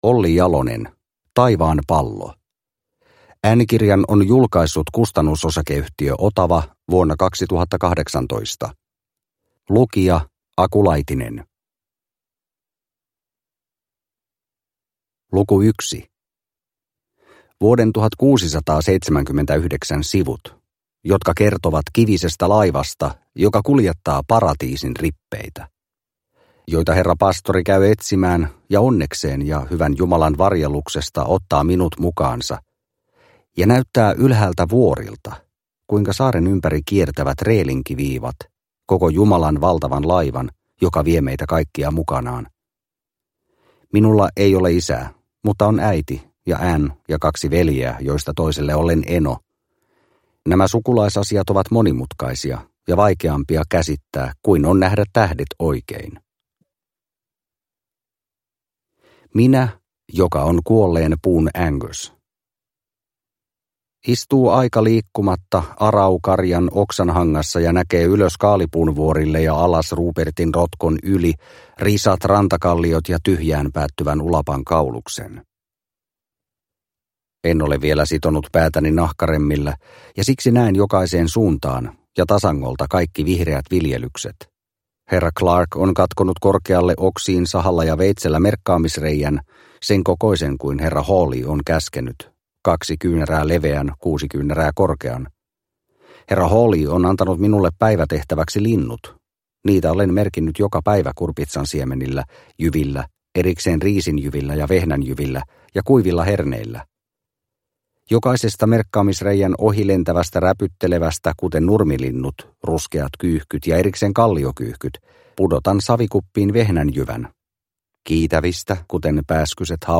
Taivaanpallo – Ljudbok – Laddas ner